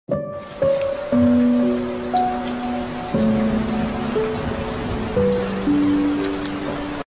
Escape to a cozy bedroom sound effects free download
Escape to a cozy bedroom where the sound of heavy rain meets the serene view of the beach outside. Enjoy the perfect blend of comfort and relaxation with your favorite cartoon on screen.